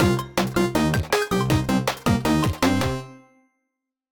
victory_nobonus.ogg